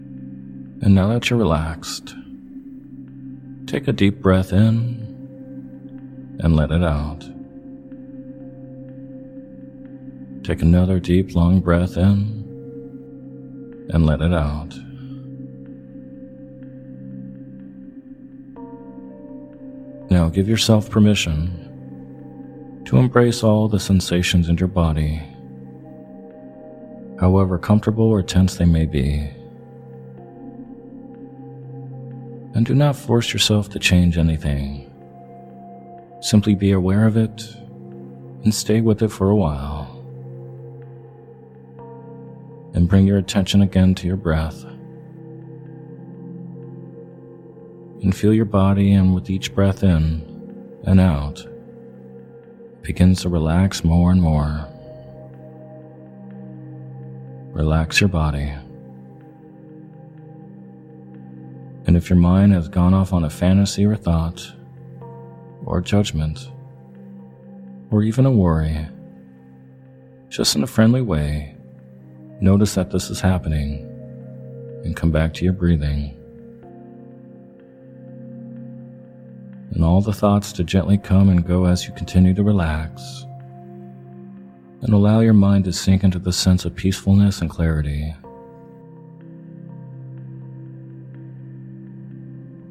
In this hypnosis audio, you’ll be guided into a visualization to help bring your soulmate into your life.